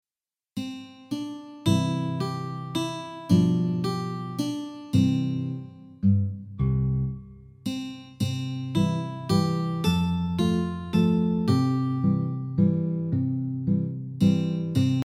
Mp3 Instrumental Track with Melody for easy learning